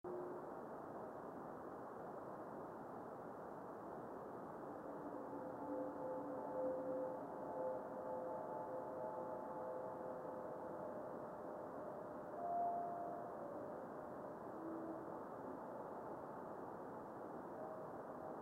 video and stereo sound:
This meteor was similar to specimen 2 above.
Radio spectrogram of the time of the above meteor.  61.250 MHz reception above white line, 83.250 MHz below white line.